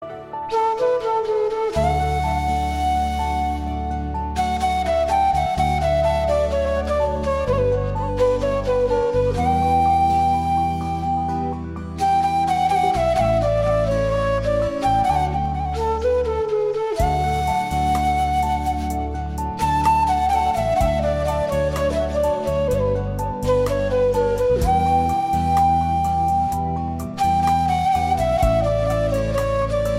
flute
instrumental